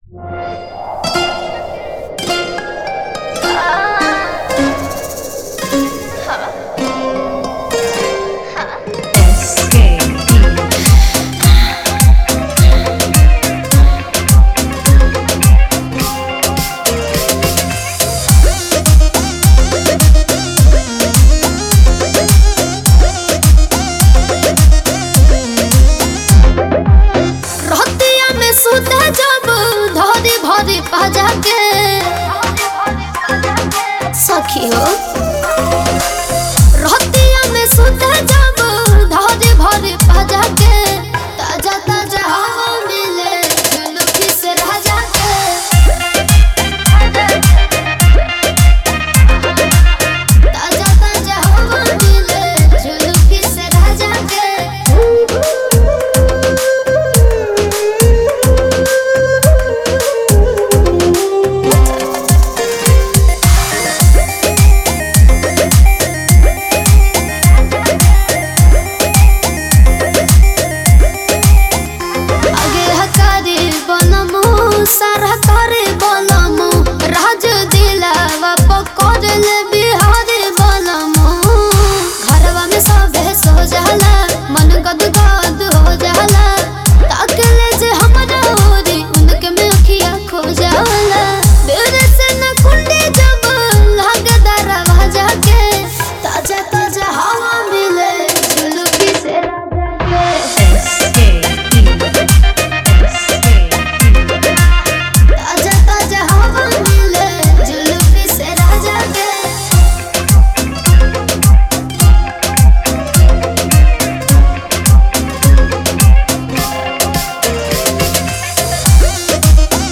All Dj Remixer